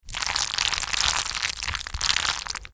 На этой странице собраны разнообразные звуки, связанные с макаронами: от шуршания сухих спагетти до бульканья кипящей воды.